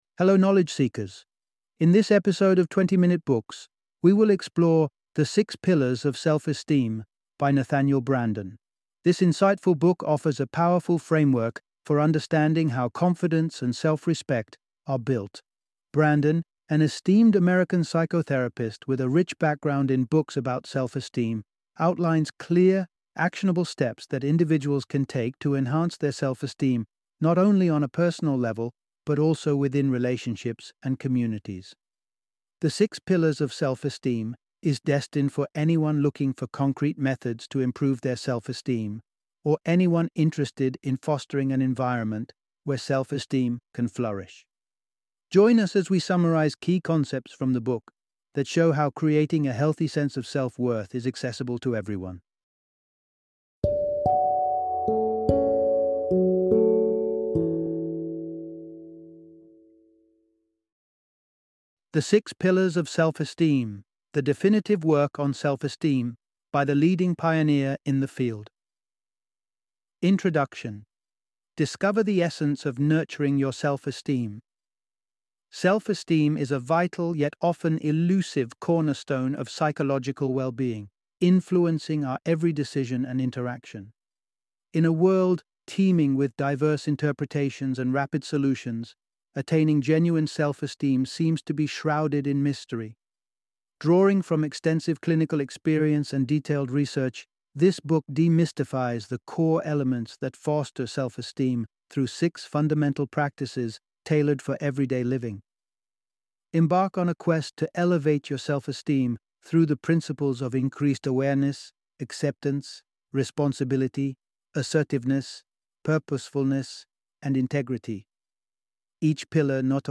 The Six Pillars of Self-Esteem - Audiobook Summary